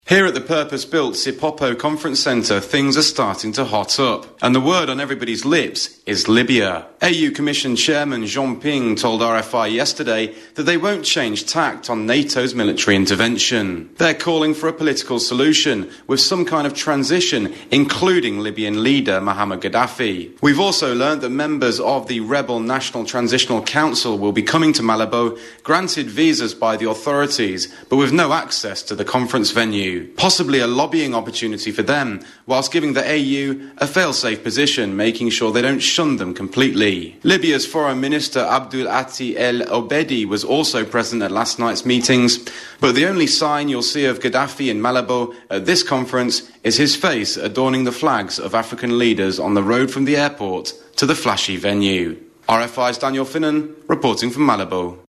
Report: Summit curtain raiser, 29th June 2011